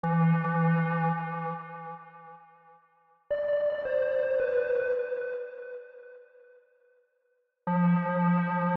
Tag: 110 bpm Funk Loops Synth Loops 1.47 MB wav Key : Unknown